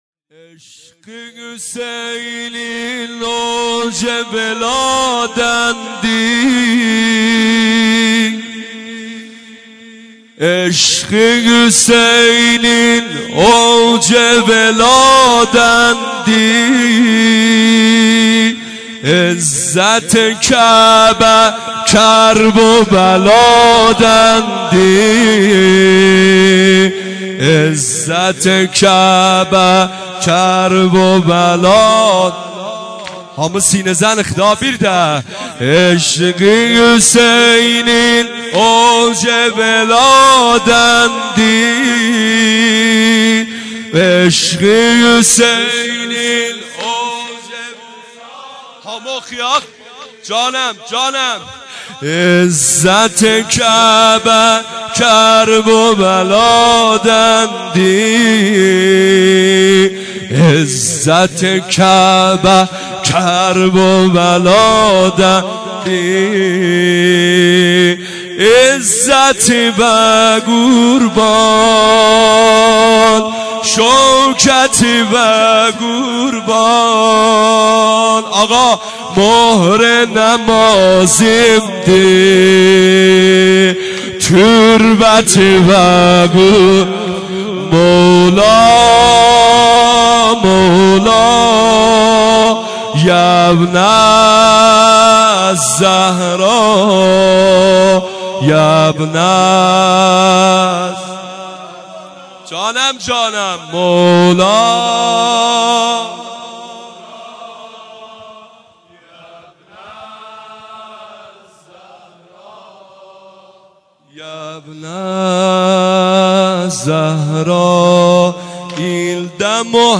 سینه سنگین | عشق حسینین
سینه زنی سنگین مداحی جدید